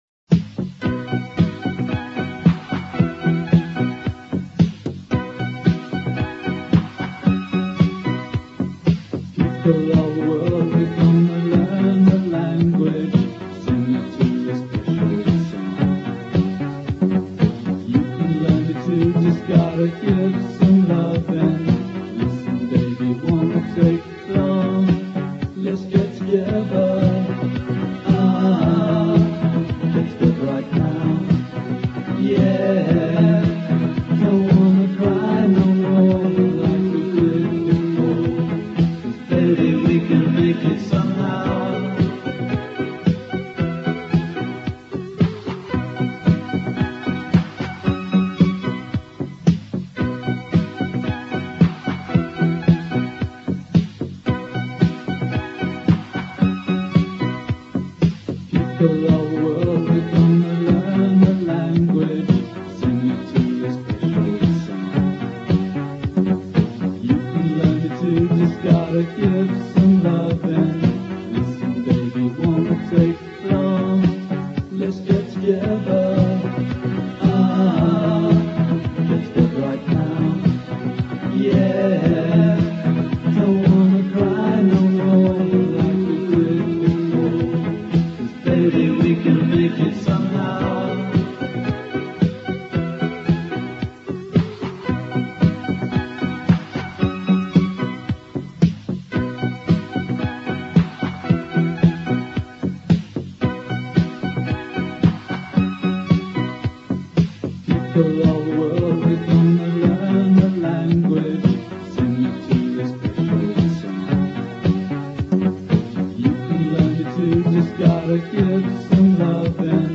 Rare early track